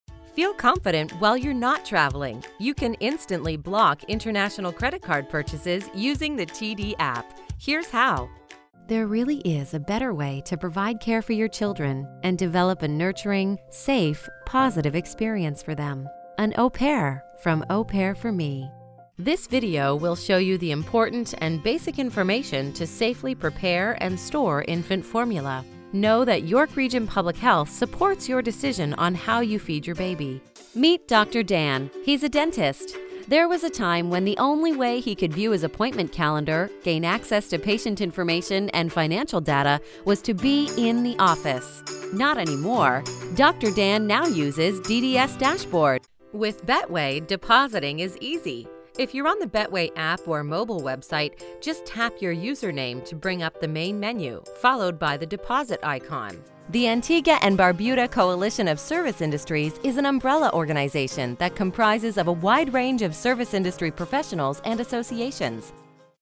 English (Canadian)
Explainer Videos
I have a neutral Canadian/American accent and work from my professional home studio daily.
My warm, trustworthy voice has been used by clients worldwide.
Studio: Custom built, double-walled and acoustically-treated home studio
Mic: Neumann TLM102